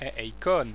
ei[ej]
La prononciation ici proposée est la prononciation qui a cours actuellement, en milieu scolaire, en France.